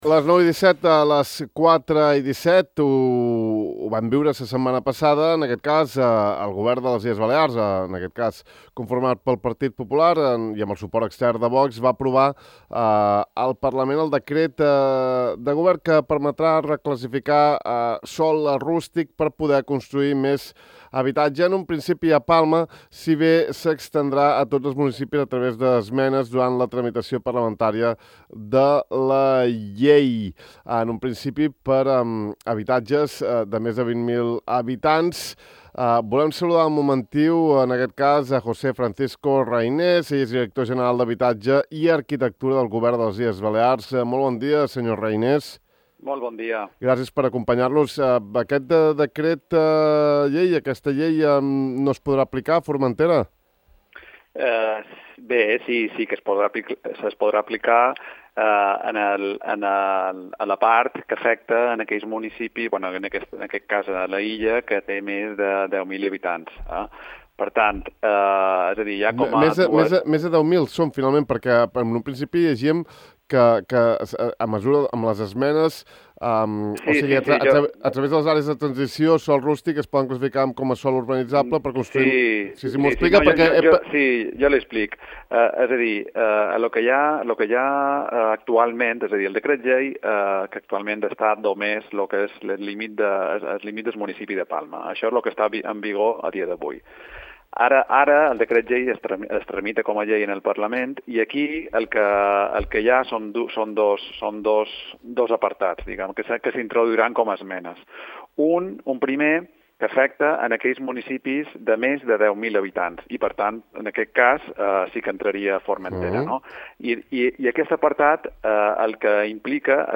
Així ho ha explicat José Francisco Reynés, director general d’Habitatge i Arquitectura del Govern balear, que ha aclarit que l’actual decret llei de projectes residencials estratègics està en vigor només a Palma.